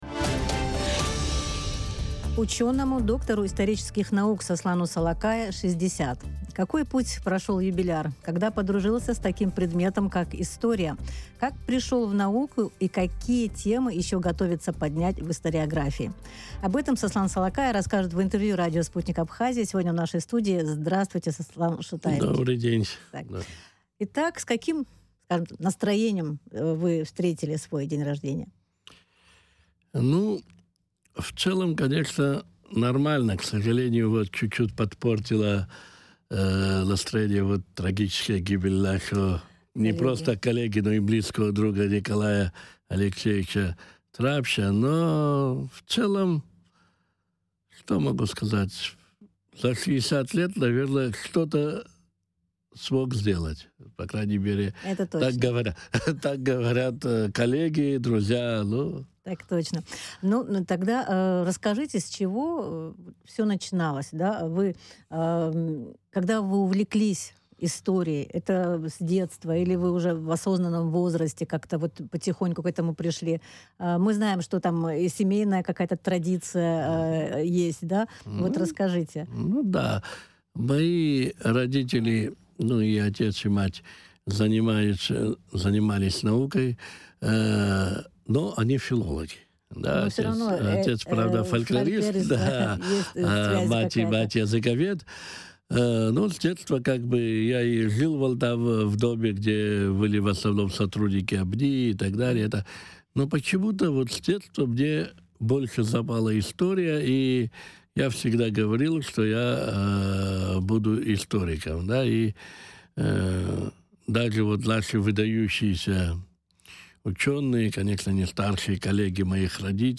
Интервью с юбиляром